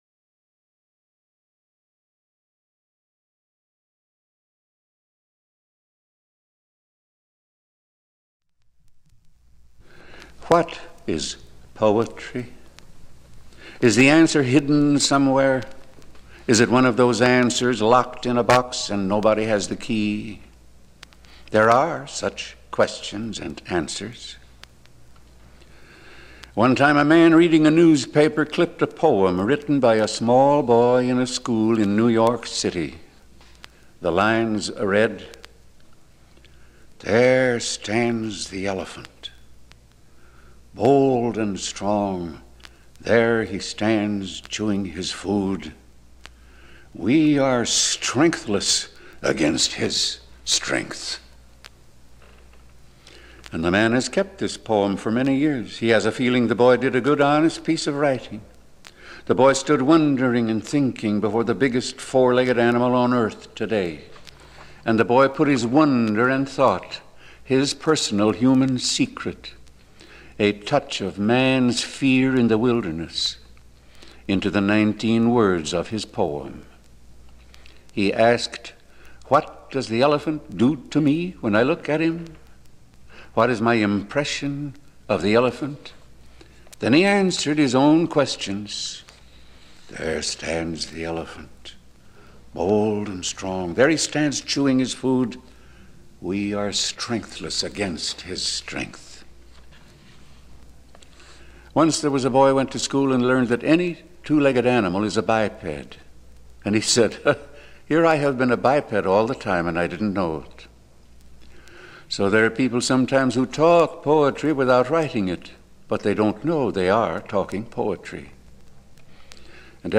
Carl Sandburg Reads Poetry For Children, 1959